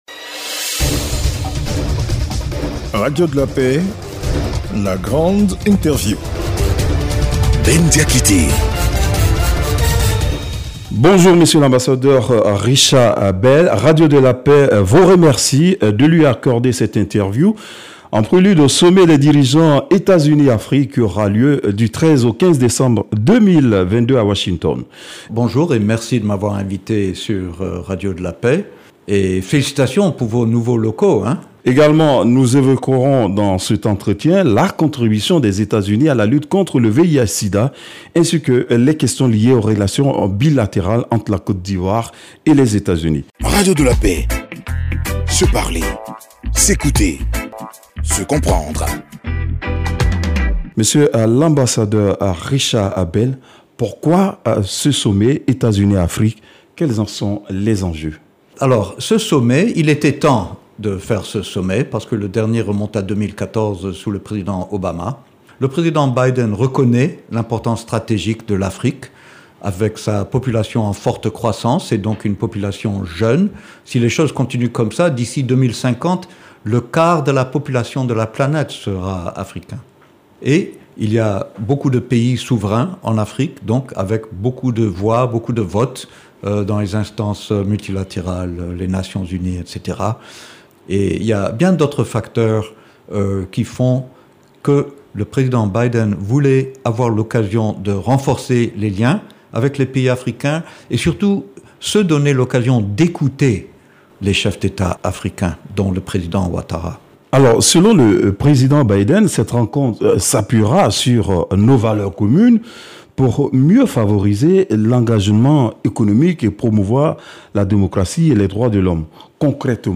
Retrouvez ce Week end dans la grande interview. M. Richard Bell Ambassadeur des États-Unis en Côte d’Ivoire. Le diplomate Américain reviendra sur les enjeux du somment des dirigeants États-Unis-Afrique qui aura lieu du 13 au 15 décembre 2022 à Washington.